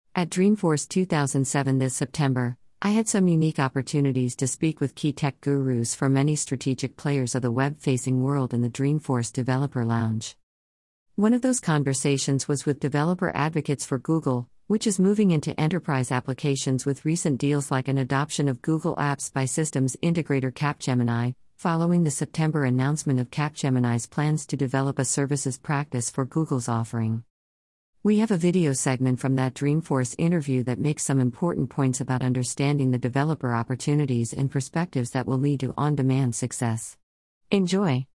At Dreamforce 2007 this September, I had some unique opportunities to speak with key tech gurus for many strategic players of the Web-facing world in the Dreamforce Developer Lounge.
We have a video segment from that Dreamforce interview that makes some important points about understanding the developer opportunities and perspectives that will lead to on-demand success.